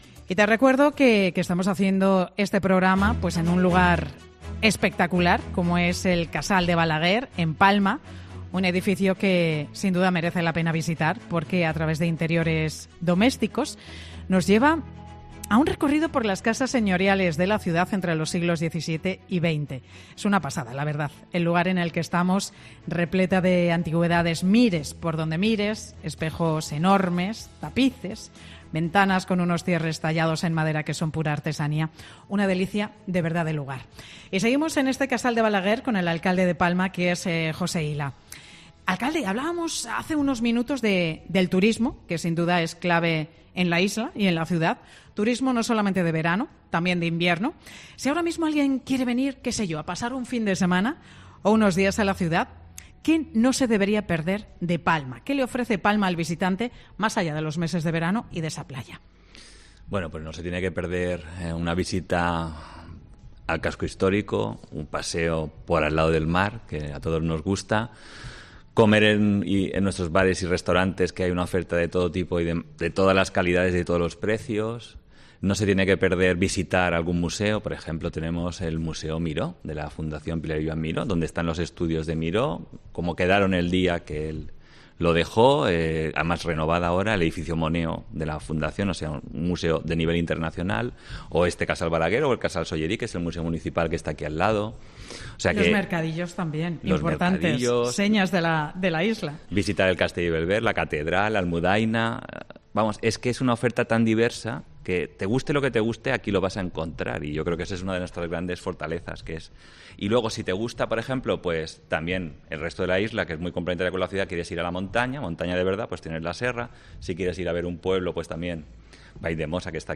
'Mediodía COPE' entrevista al alcalde de Palma sobre los preciosos parajes naturales de la capital de la isla